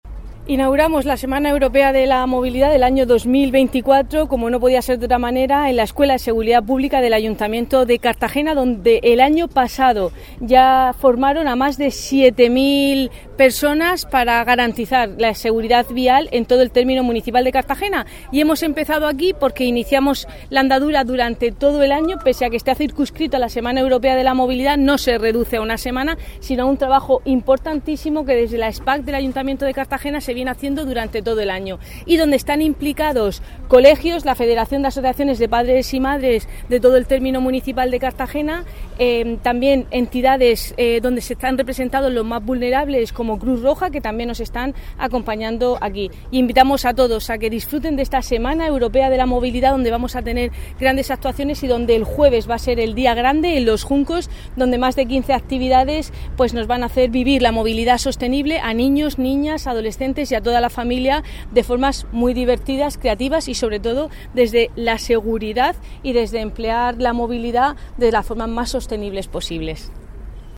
Declaraciones de la edil Cristina Mora.